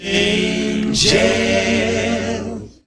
In Jail.wav